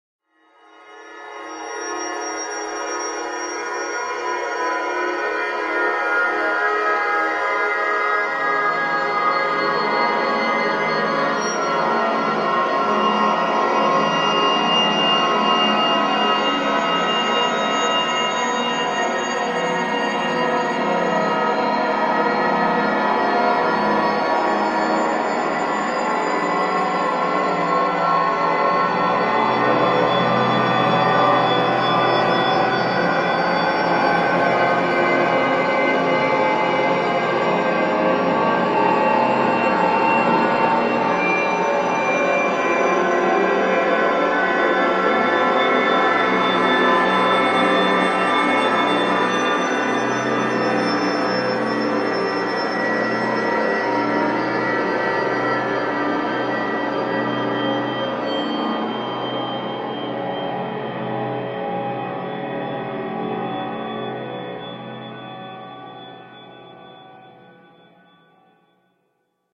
Horror-scary-ambience.mp3